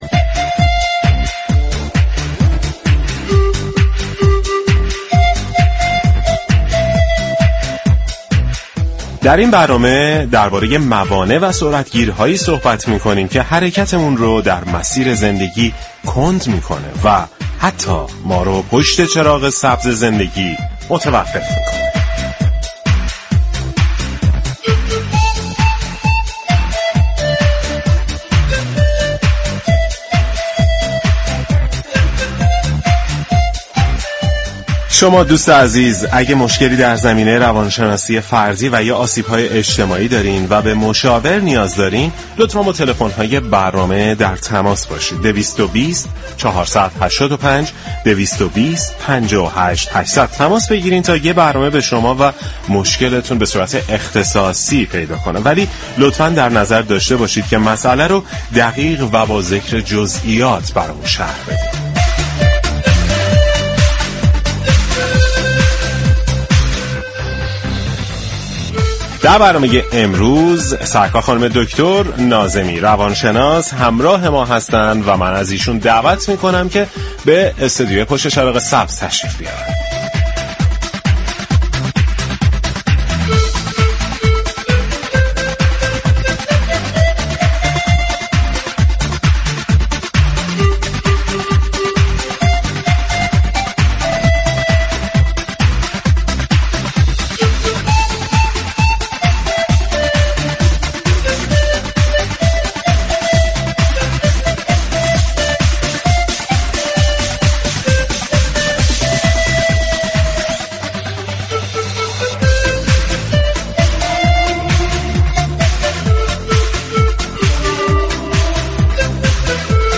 در این برنامه شنونده ای در تماس با برنامه ی پشت چراغ سبزمشكل خود را شرح داد و گفت :یكسال قبل از كنكور دچار وسواس شده و نتوانستم در كنكور شركت كنم و مجبور به ترك تحصیل شدم به دلیل این مشكلات دچار افسردگی شده و اراده ی خود را از دست دادم و در هنگام تصمیم گیری های جدی دچار مشكل می شوم .